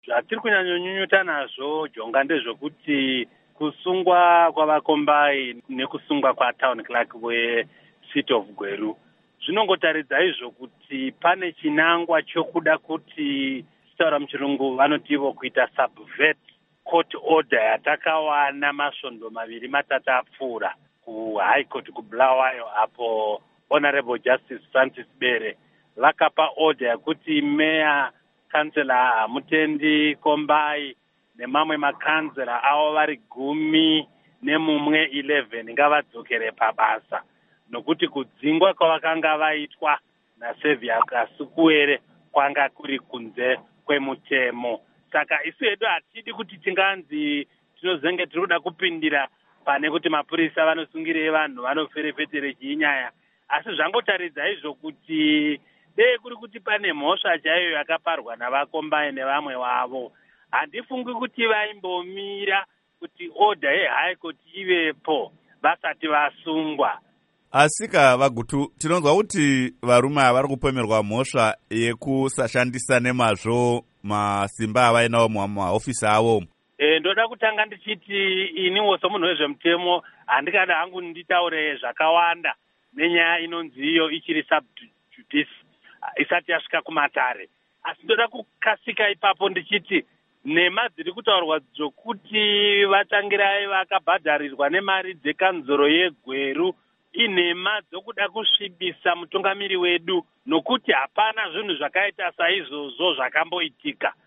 Embed share Hurukuro naVaObert Gutu by VOA Embed share The code has been copied to your clipboard.